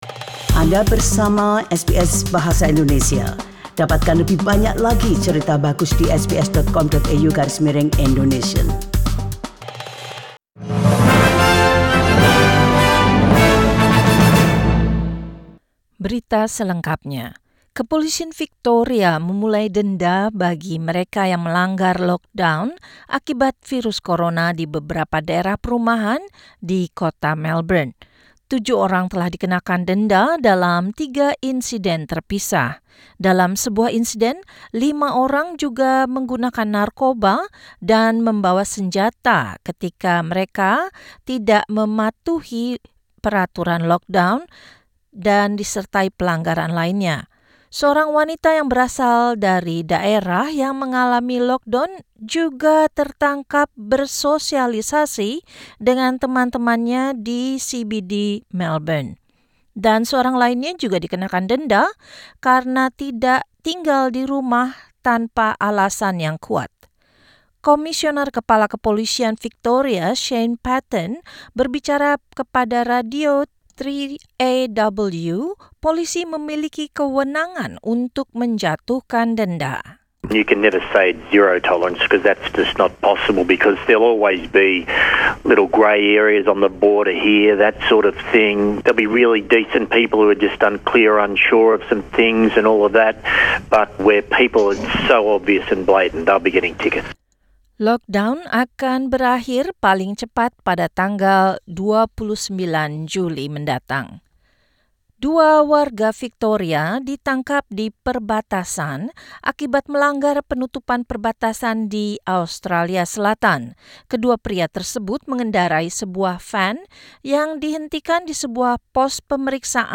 SBS Radio News in Indonesian - 3 July 2020